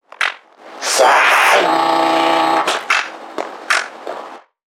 NPC_Creatures_Vocalisations_Infected [90].wav